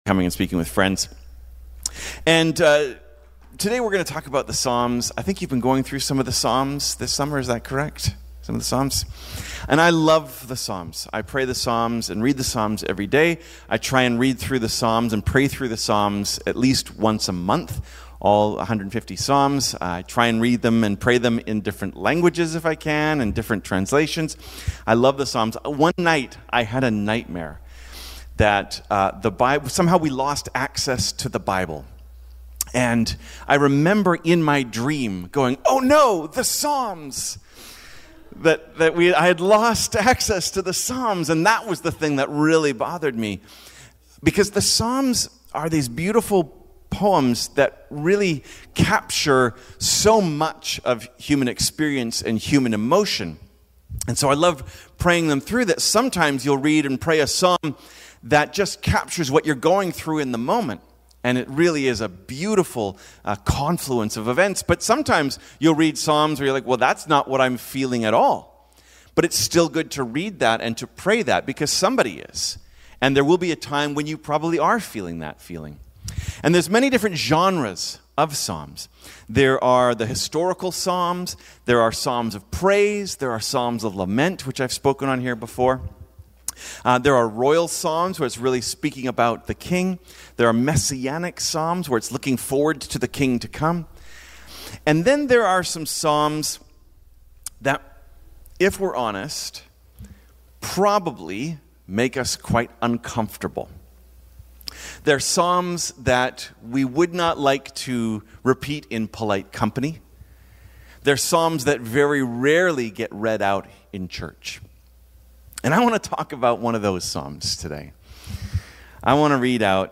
Cascades Church Sermons The Angry Psalms Play Episode Pause Episode Mute/Unmute Episode Rewind 10 Seconds 1x Fast Forward 30 seconds 00:00 / 37:10 Subscribe Share Apple Podcasts RSS Feed Share Link Embed